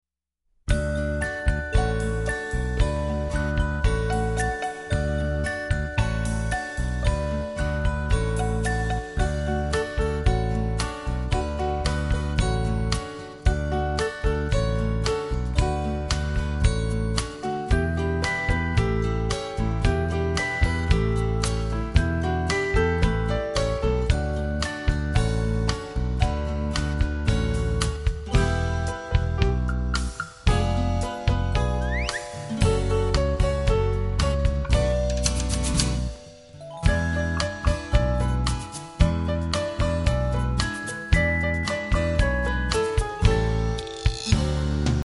Listen to a sample of the instrumental.